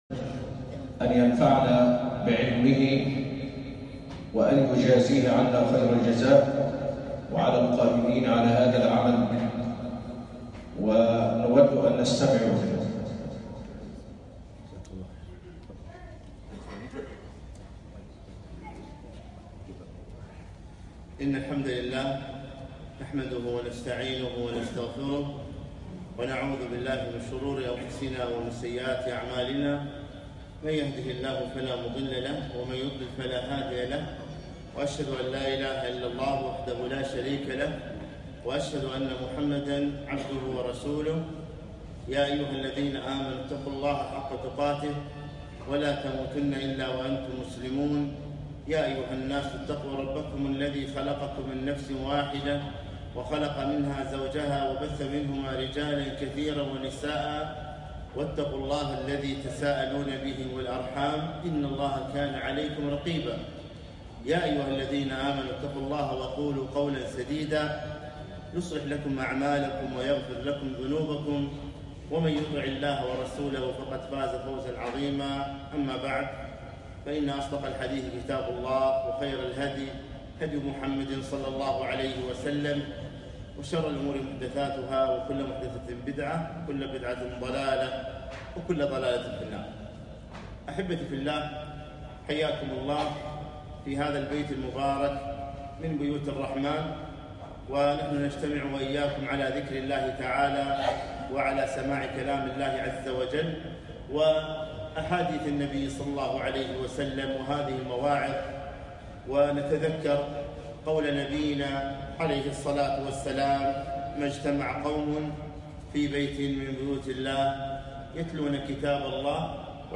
ولا تنسوا الفضل بينكم - محاضرة